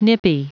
Prononciation du mot nippy en anglais (fichier audio)
Prononciation du mot : nippy